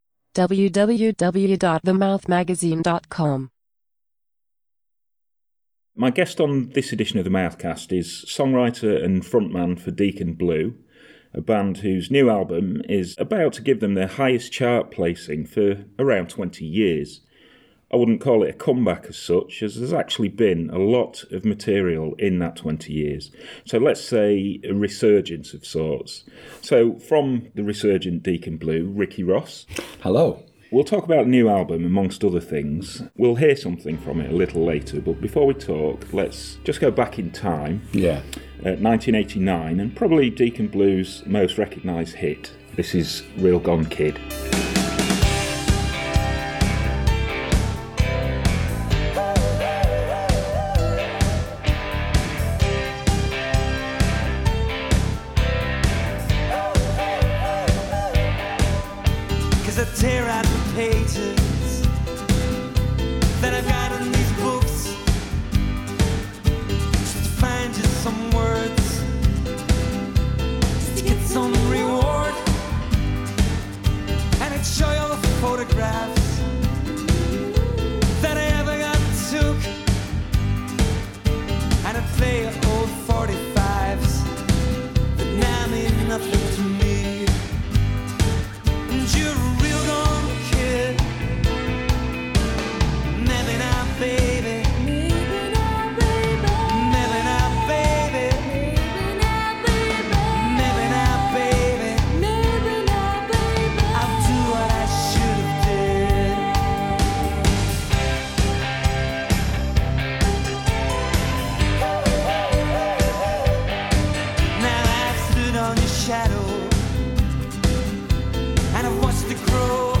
In this intimate new edition of The Mouthcast, recorded backstage before an invite-only solo show this week, Ross reflects on the band’s early days in mid-1980s Glasgow and talks about the thirty year journey to the new album. He also discusses the current state of UK politics, including his thoughts on the possibility of an independent Scotland…